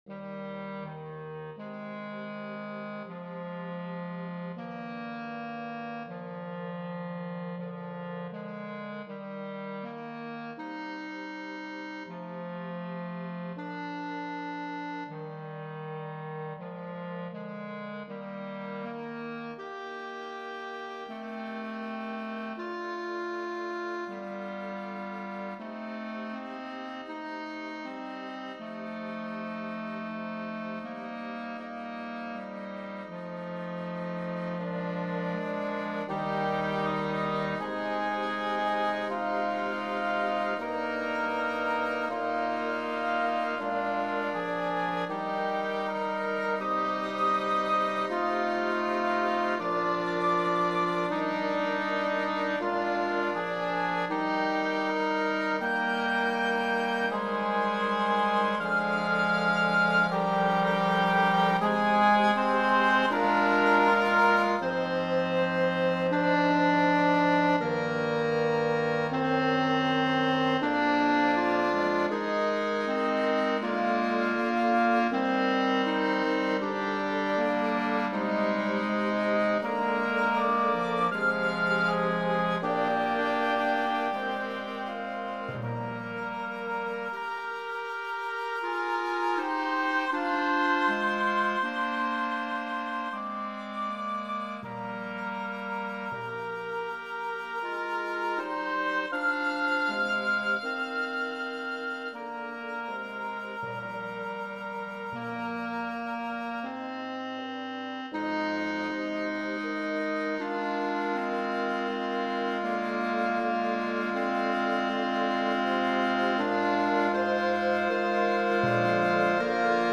Voicing: Woodwind Quintet and Timpani